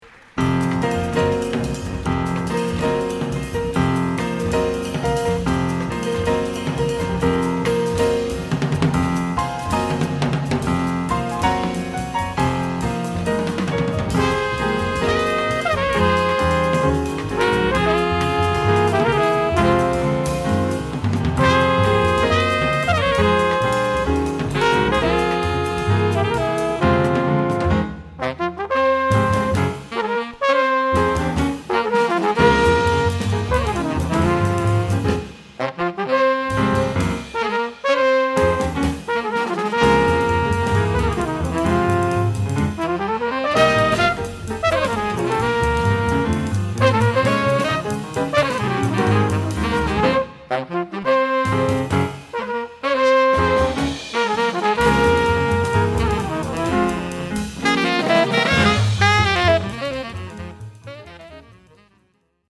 Genres: Jazz, Live.